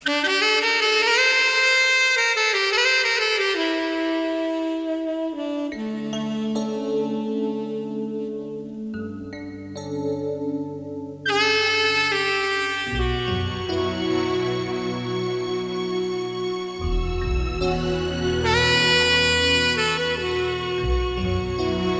Звук: Грустная саксофоновая мелодия (расставание и утрата)